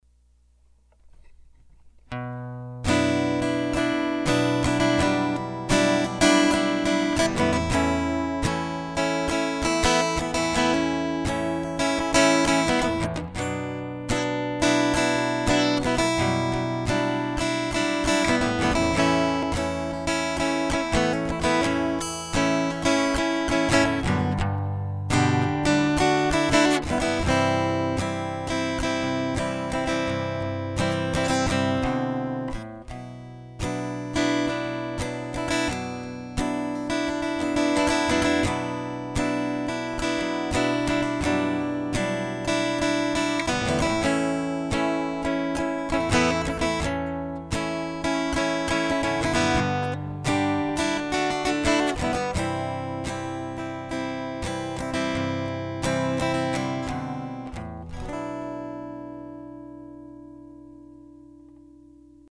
Acoustic Guitar Recording - Can't get a crisp sound (user error?)
Right away I noticed a much crisper sound.
I'm getting a little bit of choppy-ness, I'm guess from Vista running other programs in the background - but I'm not sure. Also, I'm not sure I like how you can pick up every slide of my hand...